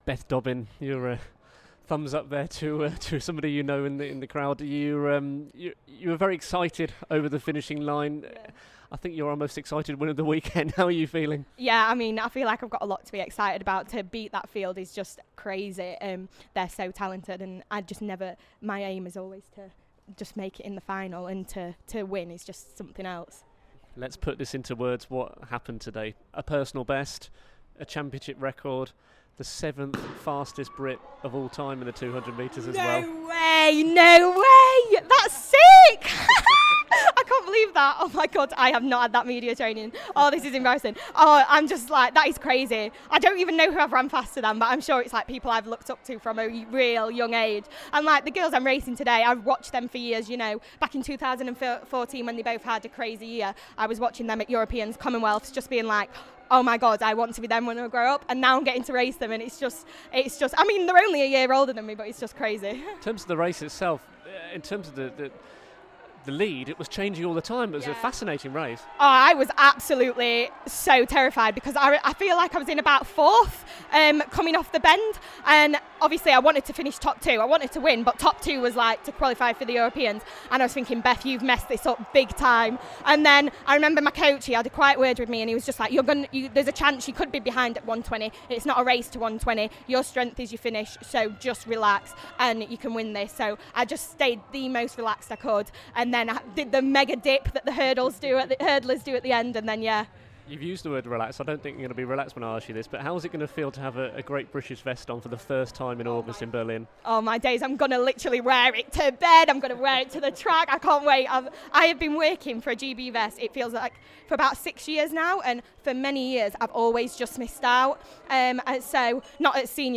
New British 200m champion Beth Dobbin couldn't contain her excitement after a huge new personal best time to claim the title on a dramatic afternoon in Birmingham.